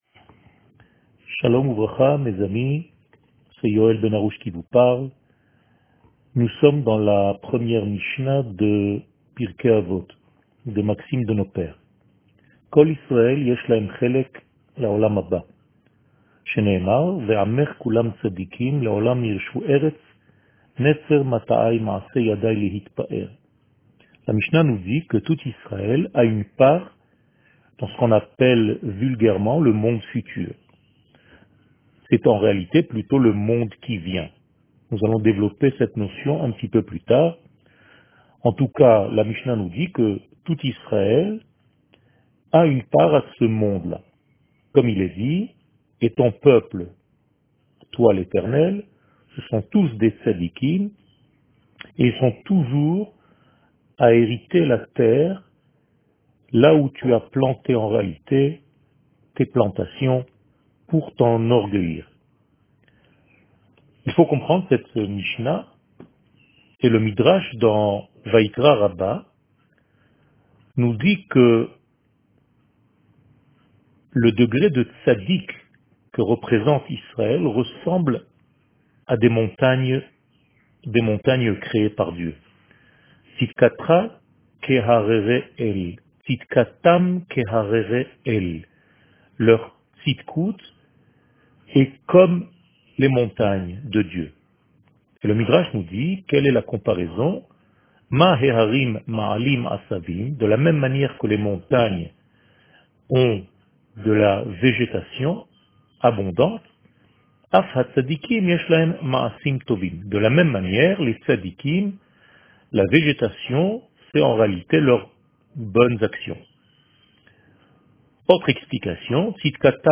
שיעור מ 19 יולי 2020